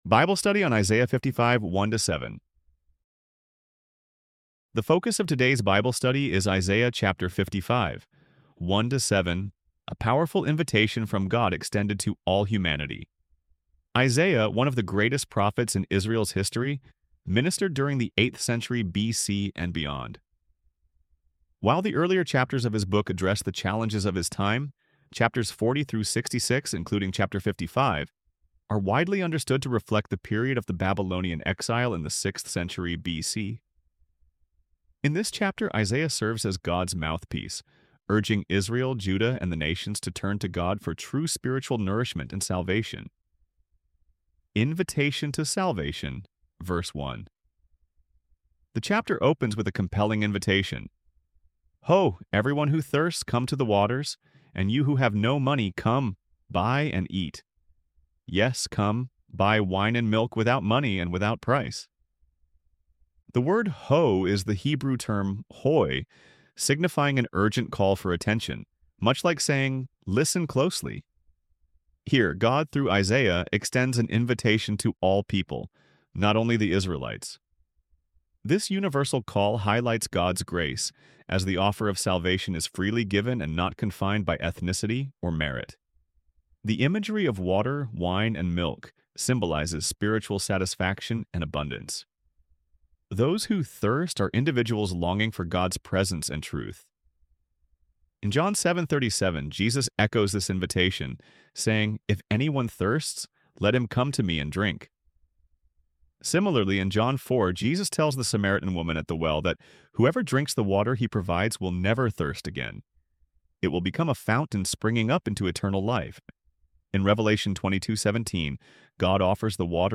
ElevenLabs_Bible_Study_on_Isaiah_55_1-7.mp3